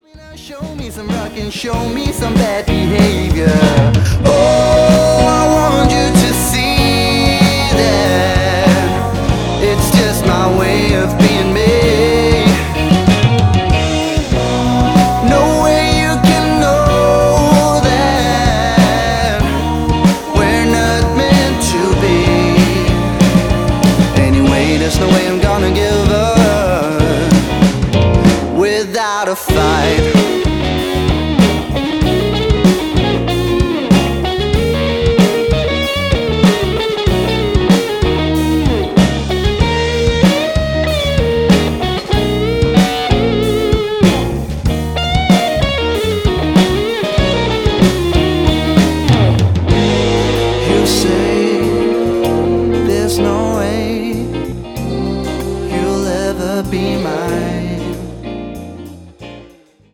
• Blues
• Country
• Pop
• Singer/songwriter